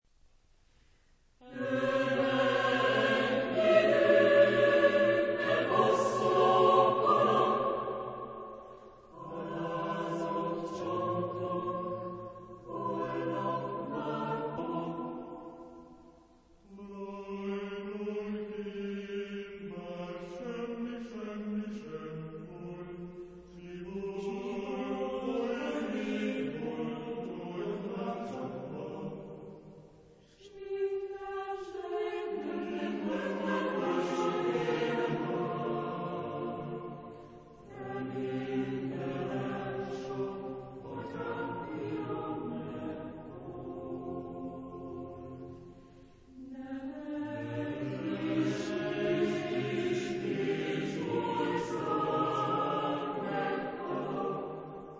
Género/Estilo/Forma: Lírica ; Coro ; ciclo
Tipo de formación coral: SATB  (4 voces Coro mixto )
Tonalidad : tonal